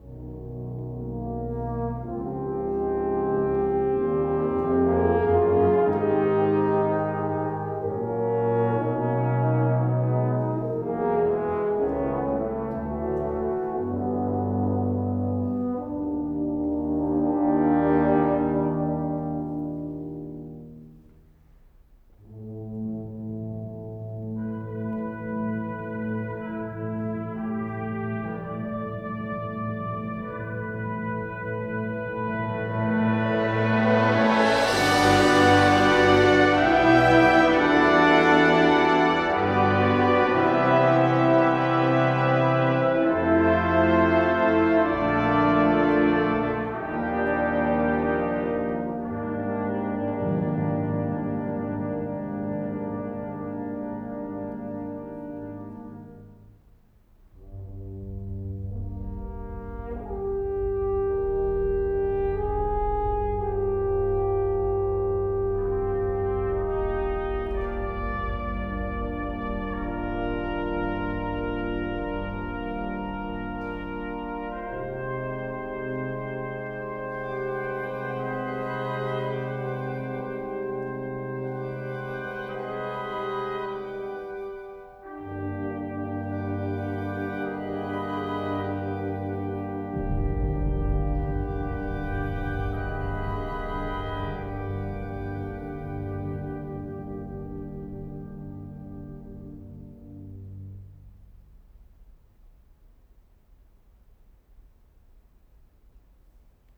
Sample #2: Wind Symphony (01:45) (9.4MB/file).
B-format files for 3 microphones.